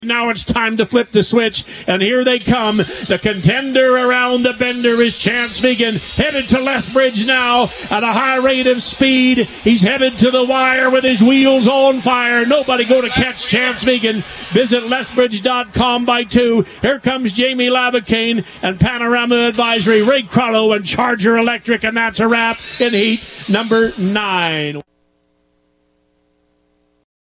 As heard on 96.5 The Ranch